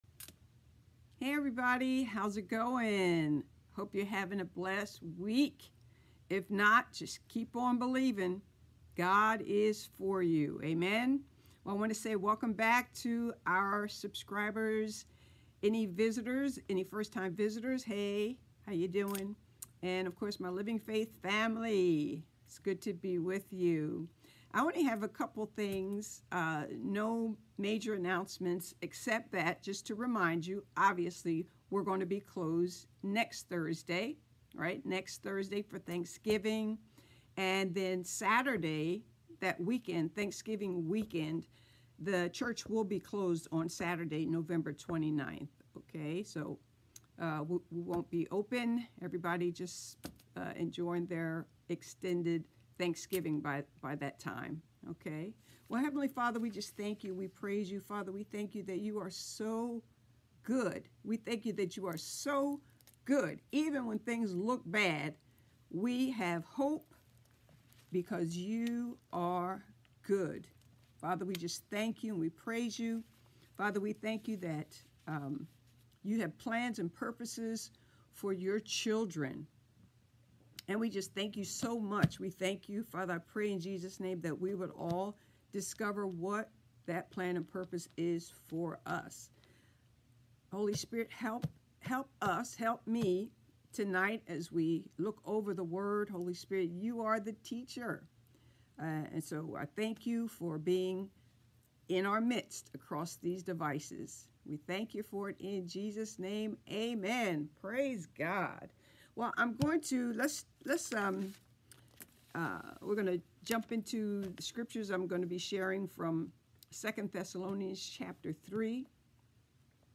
Sermons | Living Faith Christian Center